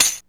Closed hat 1.wav